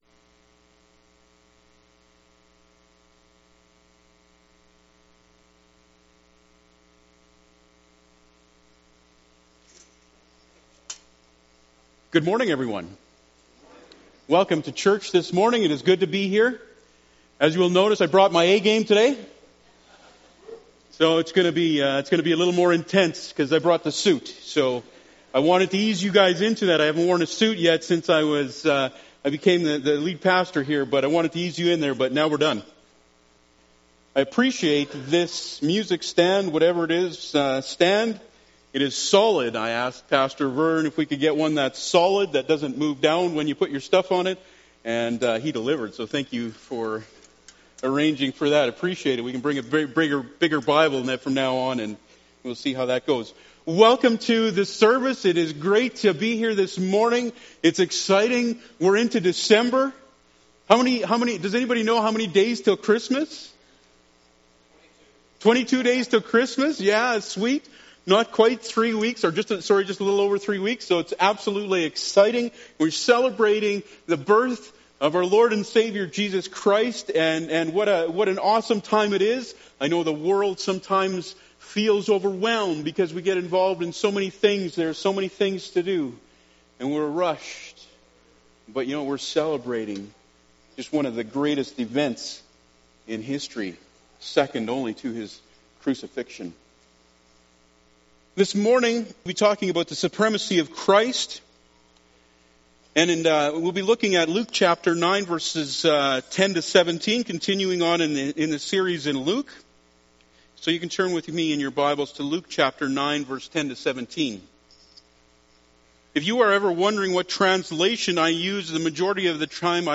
Luke 9:10-17 Service Type: Sunday Morning Bible Text